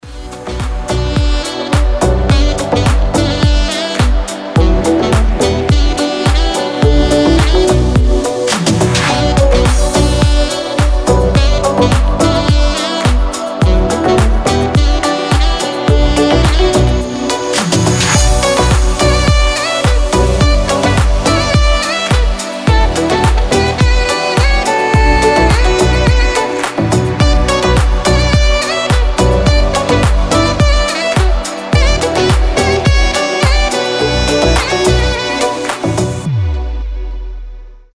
Красивая романтичная мелодия